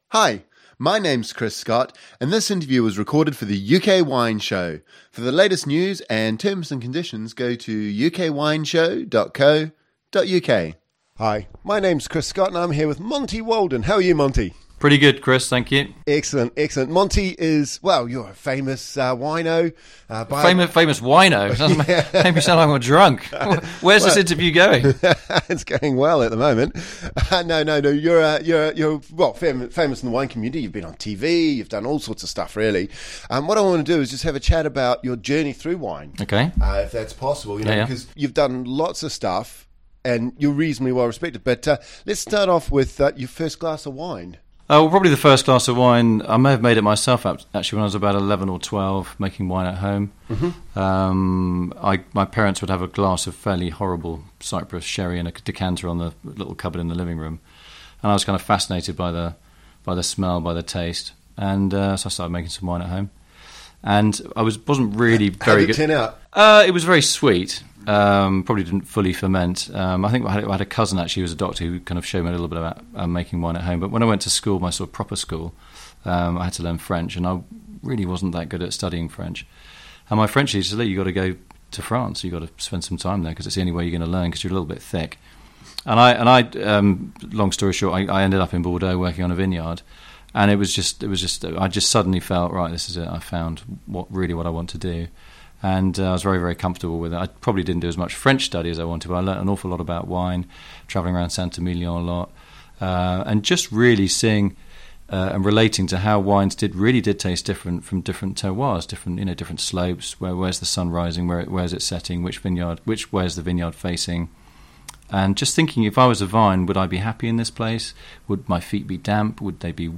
Overview In this first interview Monty Waldin tells us how he first became interested in wine, spending his time in France and Germany learning how to make wine in a hands-on capacity from 1989 onwards.